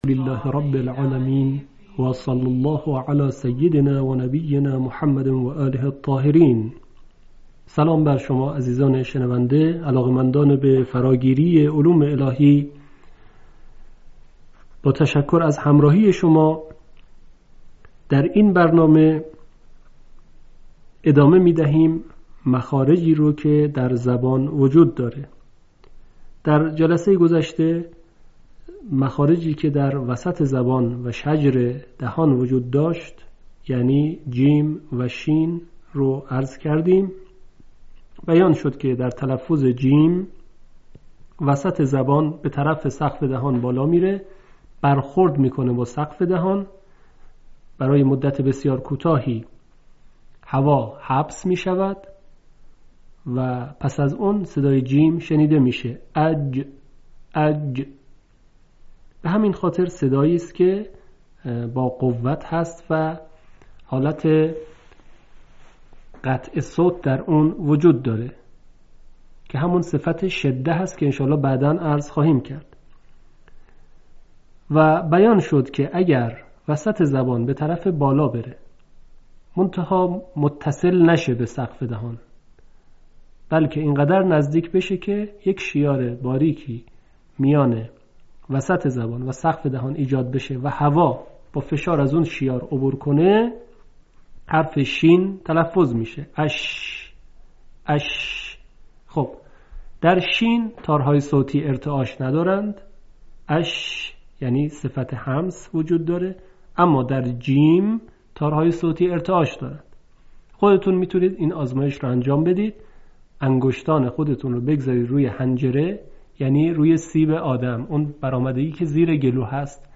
آموزش تجوید تحقیقی